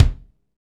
KIK FNK K03R.wav